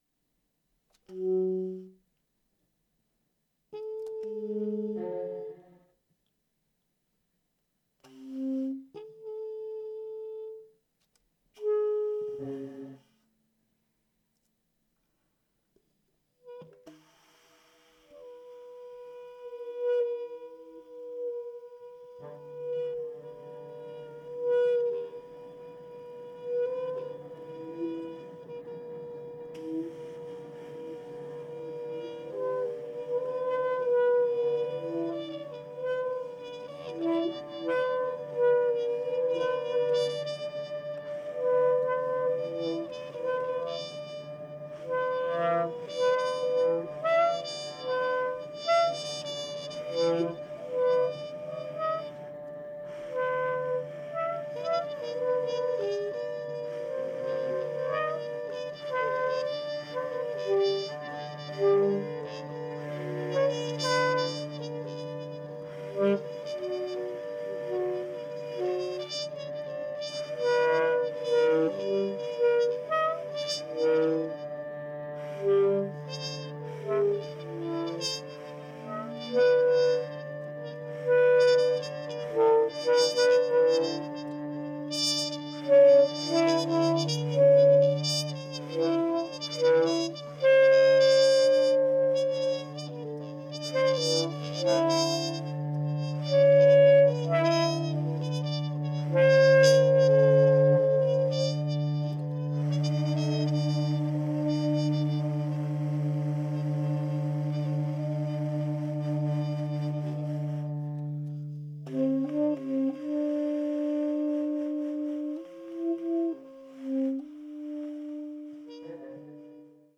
trumpet, flugelhorn
acoustic violin, electro-acoustic octave violin
tenor & baritone saxophones
trumpet, flugelhorn, objects